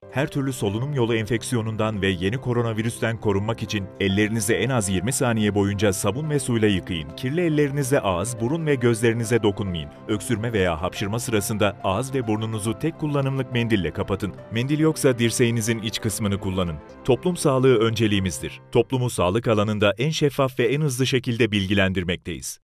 磁性男声-宣传片